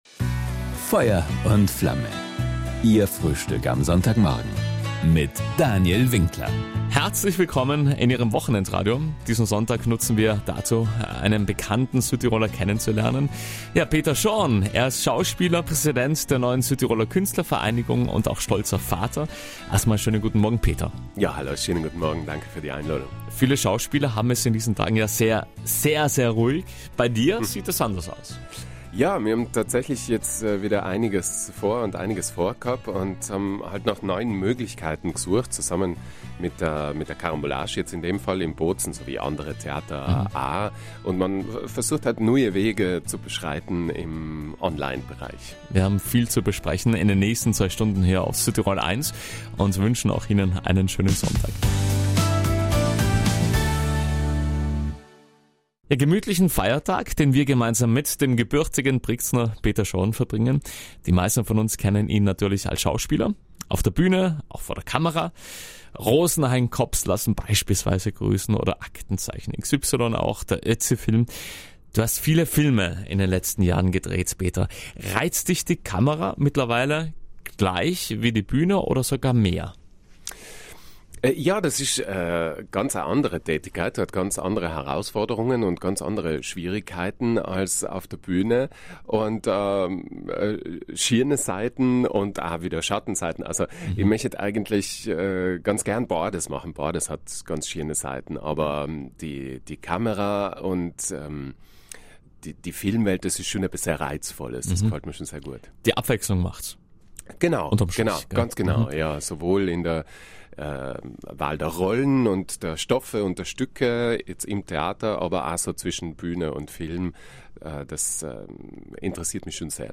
Ein Sonntagsgespräch über viele Herausforderungen, aber auch über viel Privates.